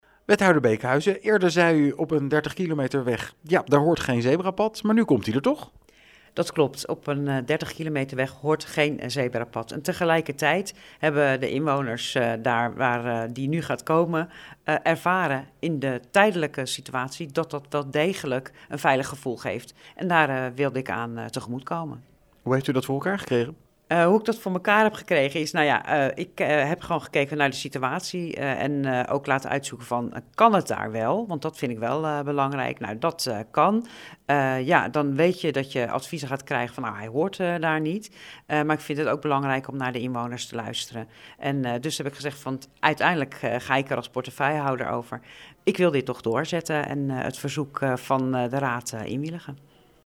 in gesprek met wethouder Angelique Beekhuizen over het zebrapad: